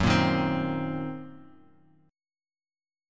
Gb6b5 chord